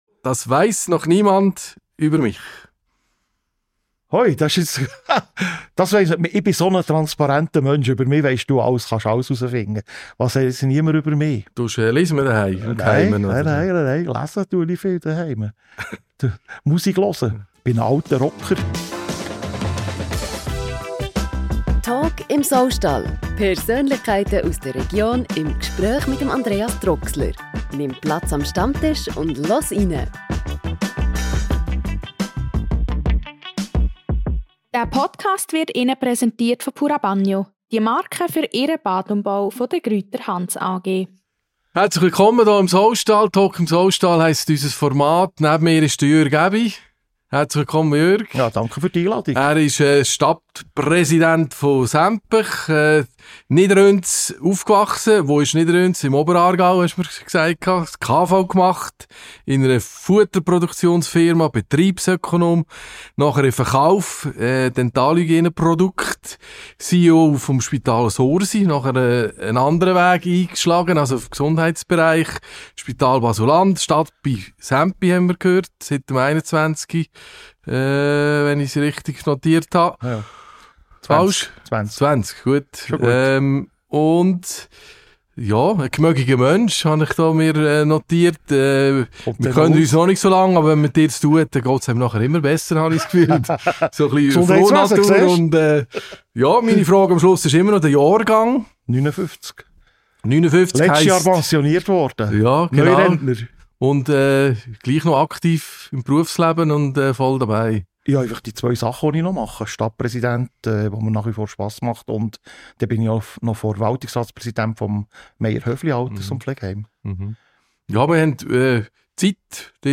Beschreibung vor 1 Jahr Im monatlichen Podcast im ehemaligen Saustall in Nottwil erfährst du mehr über die Menschen aus der Region.